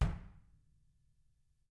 Index of /90_sSampleCDs/ILIO - Double Platinum Drums 1/CD2/Partition A/REMO KICK R